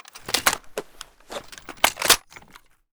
m16_reload.ogg